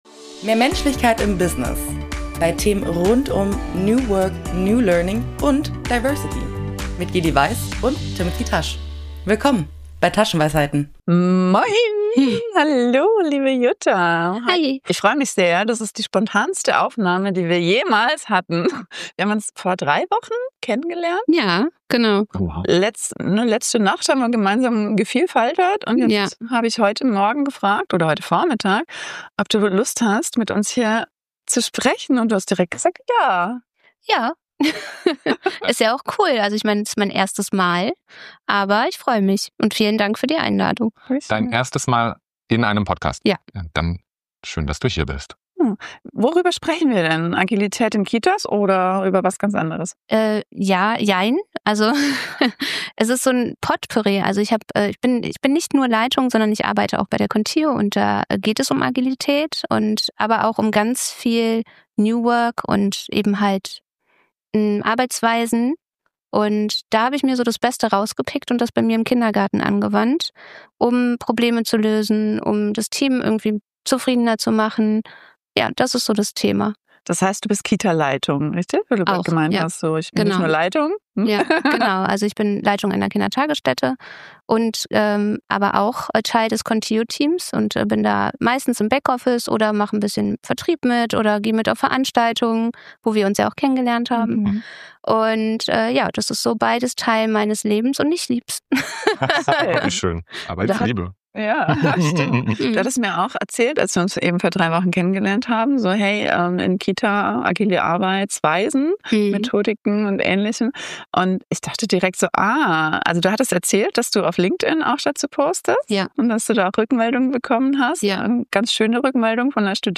Als spielerisches Element erwartet euch ein agiles Storytelling inklusive Review und Retro.